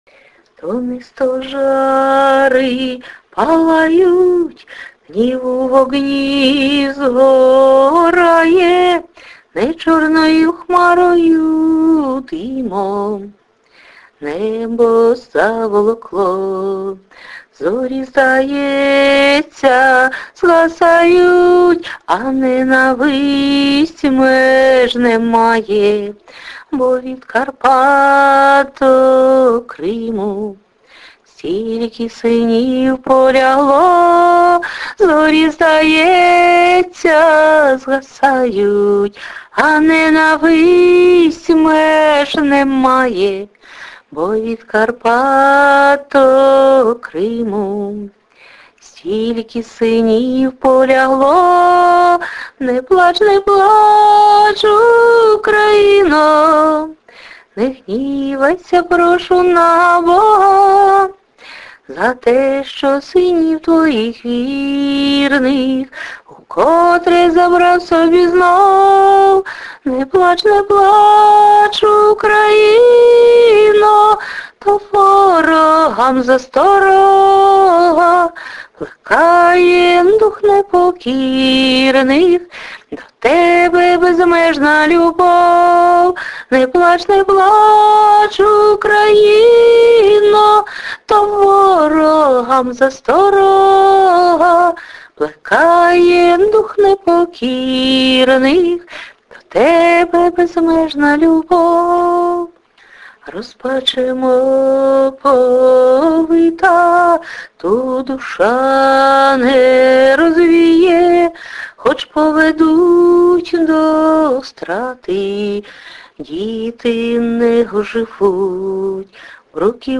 чудова пісня. додає емоцій до тексту. give_rose